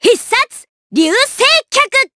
Cecilia-Vox_Skill7_short_jp.wav